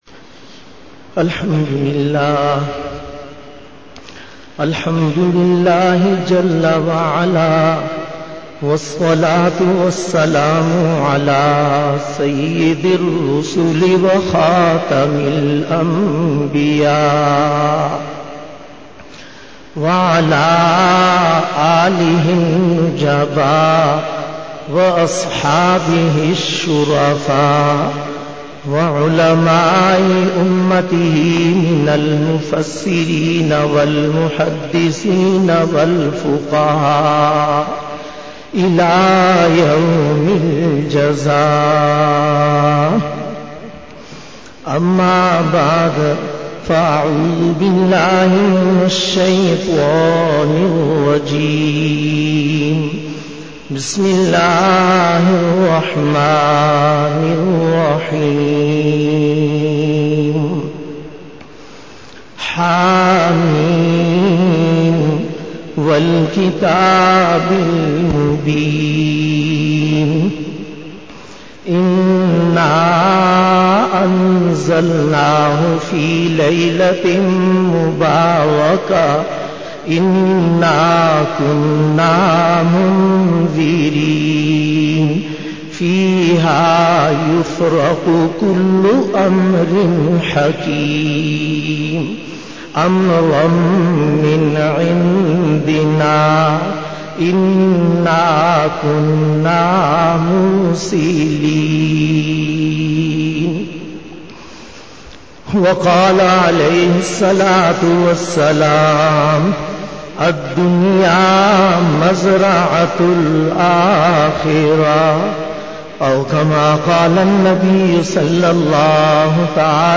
24_Bayan e Juma tul Mubarak 21-june- 2013